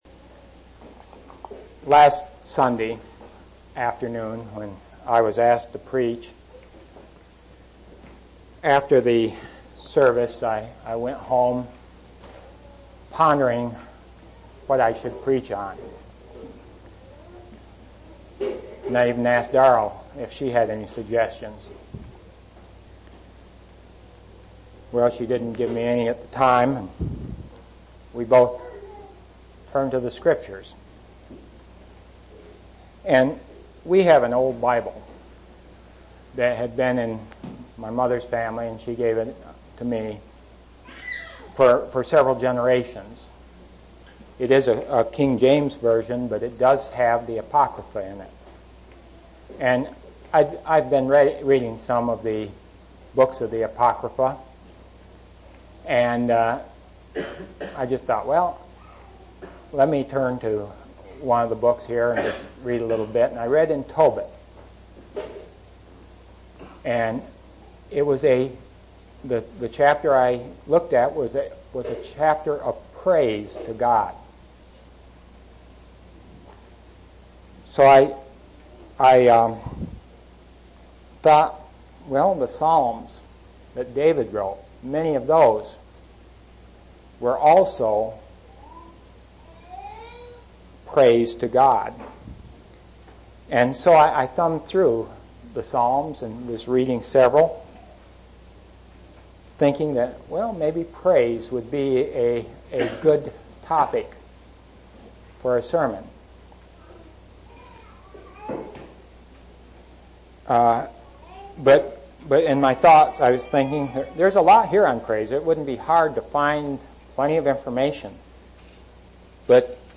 6/14/1987 Location: East Independence Local Event